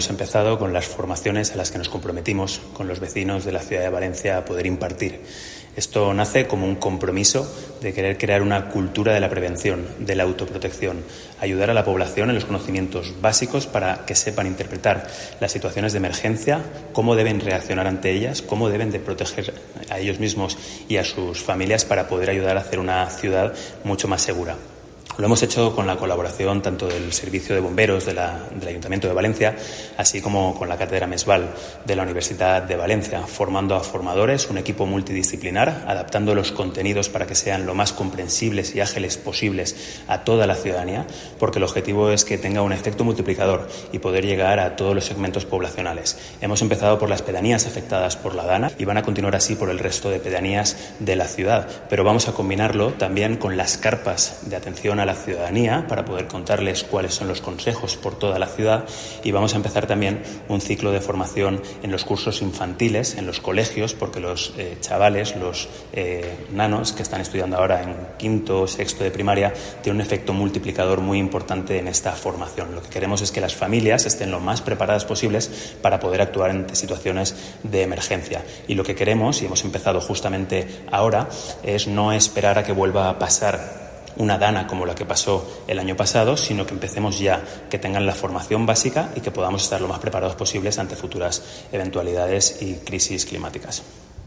Corte de voz del concejal de Emergencias, Juan Carlos Caballero.